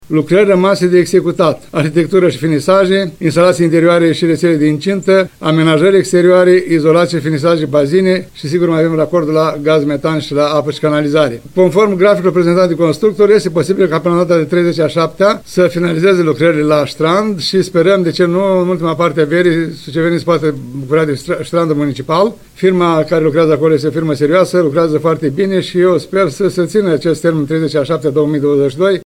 ION LUNGU a detaliat lucrările rămase de executat.